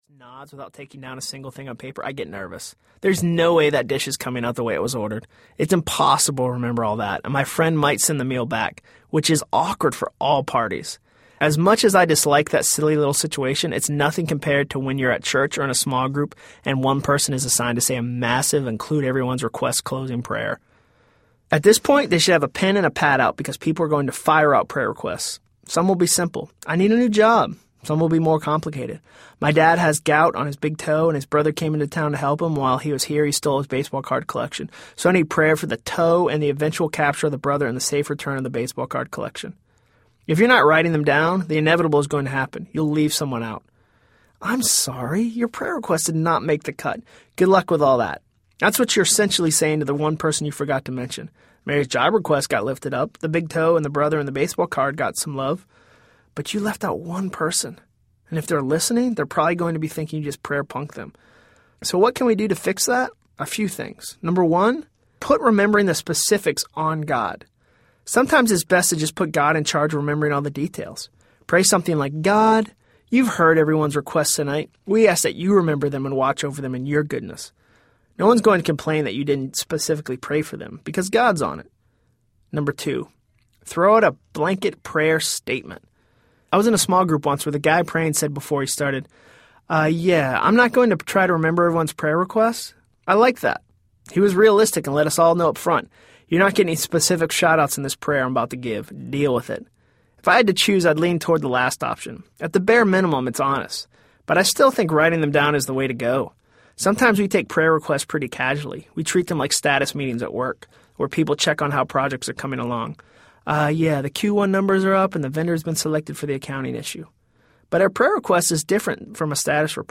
Stuff Christians Like Audiobook